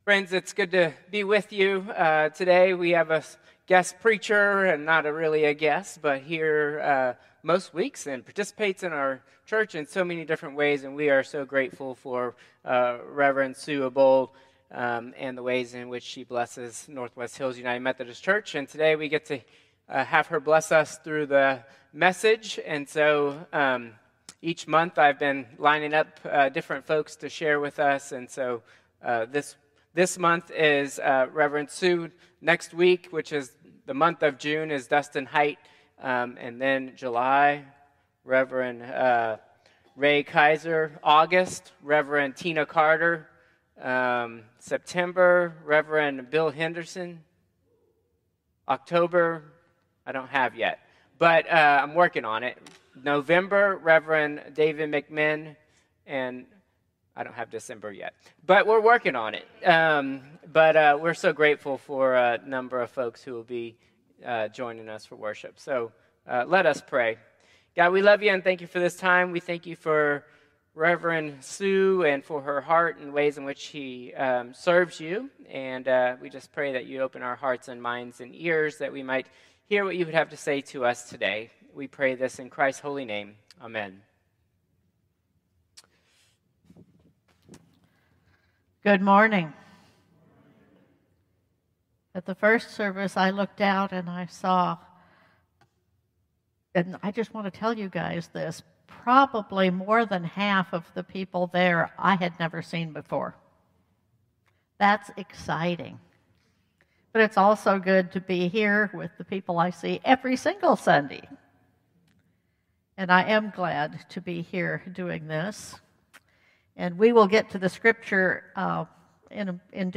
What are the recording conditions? Traditional Service 5/25/2025